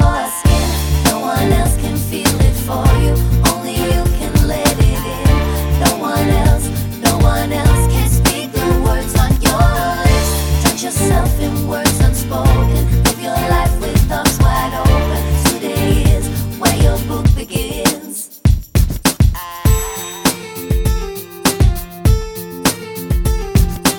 no Backing Vocals Pop (2000s) 4:27 Buy £1.50